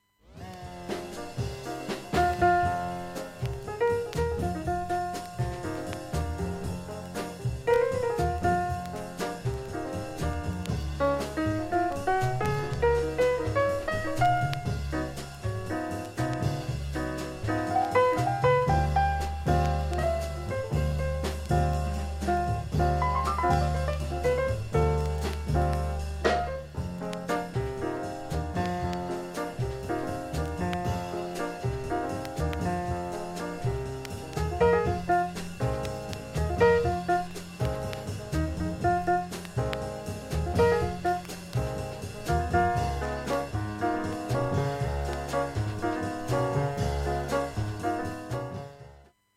音質良好全曲試聴済み。
５０秒の間にプツ出ますが、ごくかすかです。
MONO